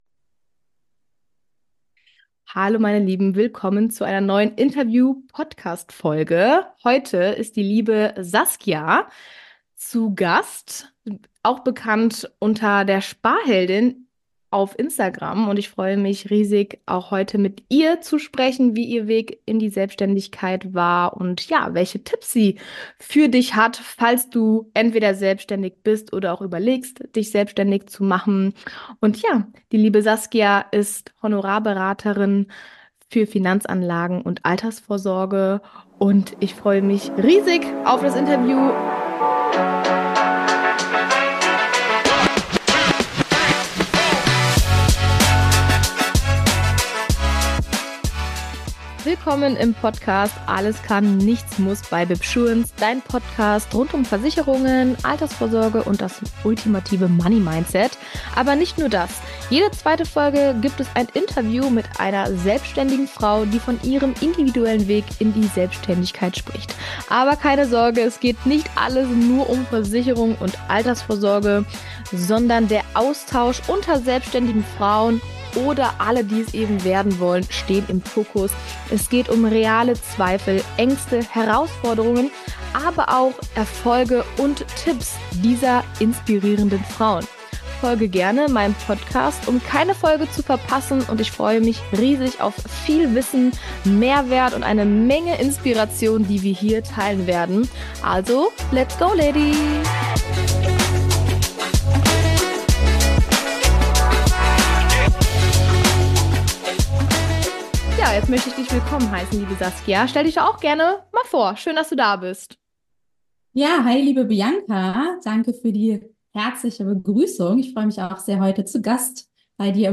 Warum du nicht zur Bank gehen solltest für deine Geldanlage, Interview